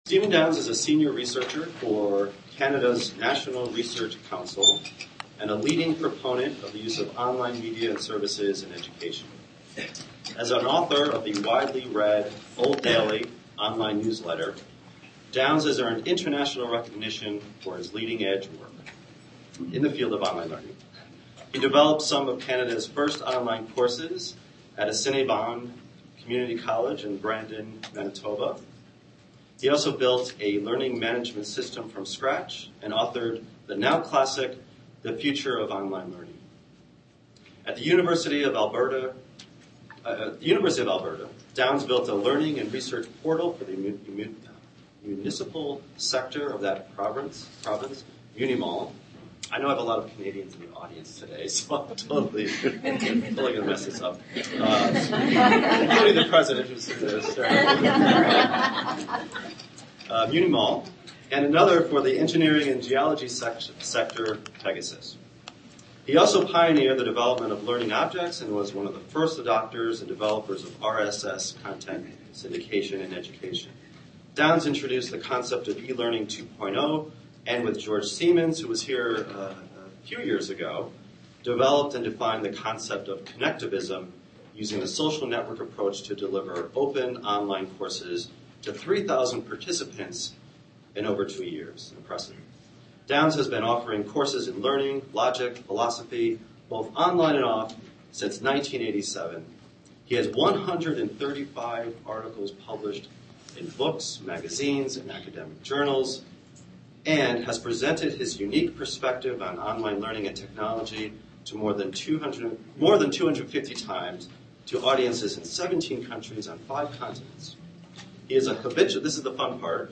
Keynote